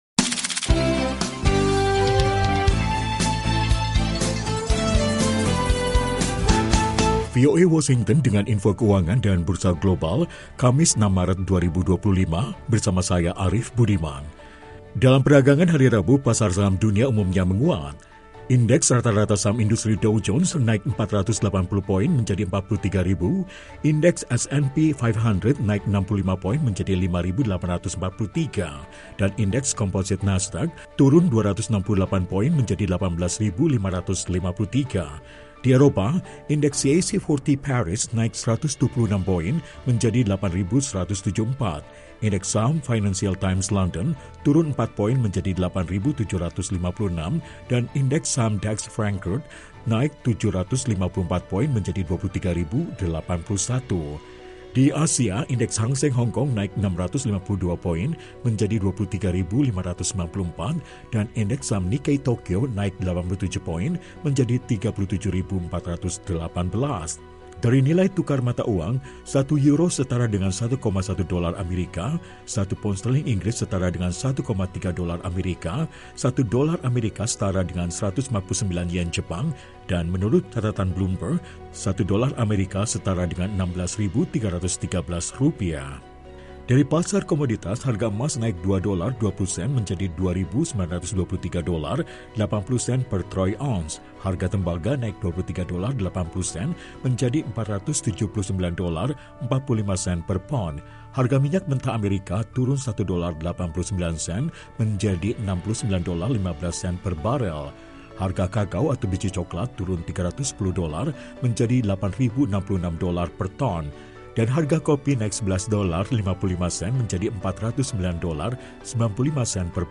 Siaran Radio VOA Indonesia